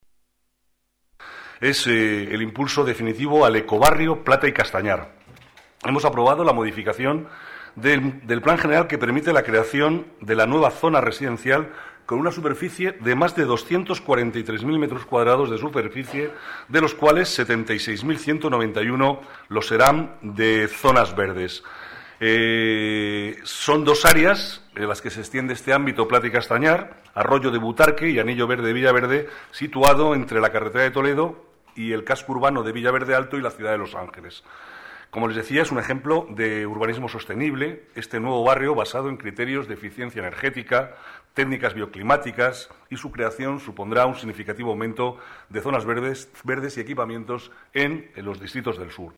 Nueva ventana:Declaraciones del vicealcalde, Manuel Cobo